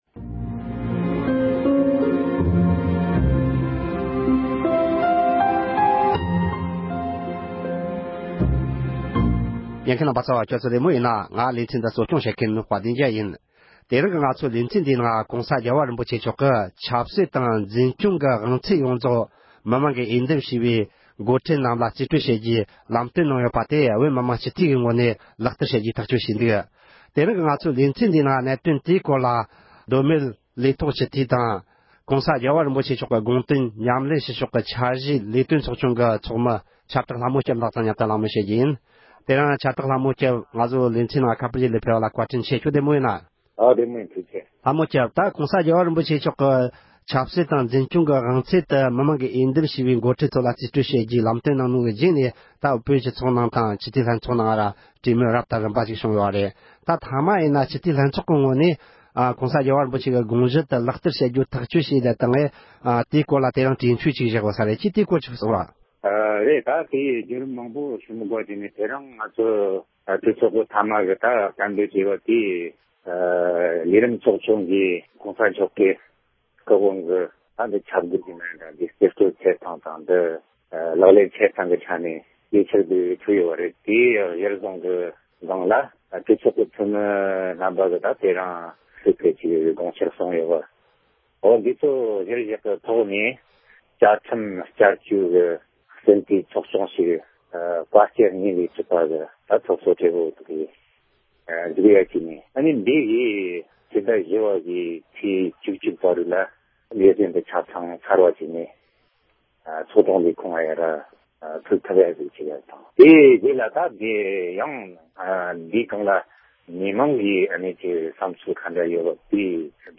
གླེང་མོལ་བྱས་པར་ཉན་རོགས་གནོངས།